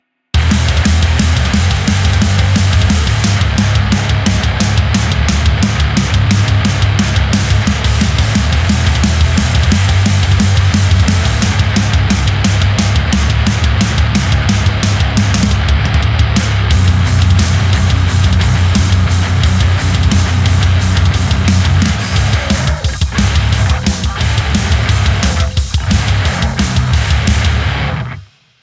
Metal Mayhem revisited Zum Vergrößern anklicken.... damit es auch freitags rumpelt und pumpelt ... hoffe, es hat eine akzeptable Grundschäbigkeit Dein Browser kann diesen Sound nicht abspielen. HM-2 Whazzzupp ins IF und durch ne Ampsim